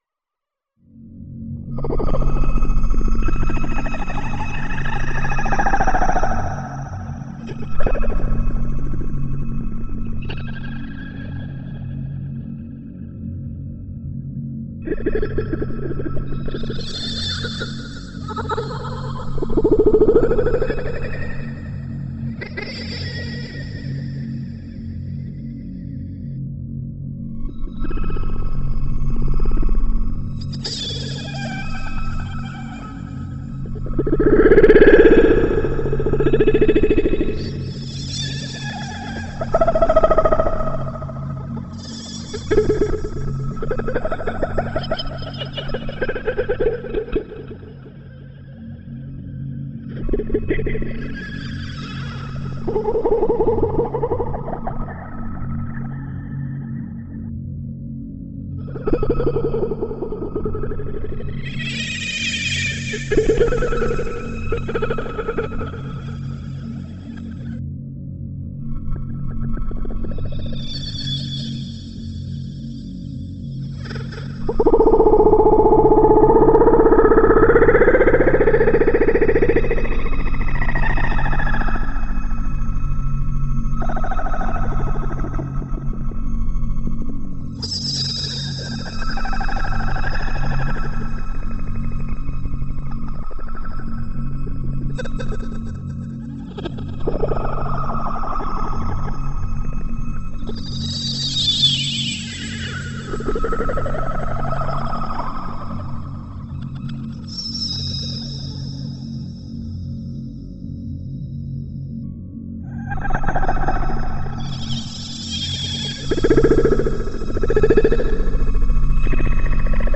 ambiant / alien_space